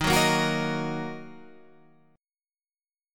D# Minor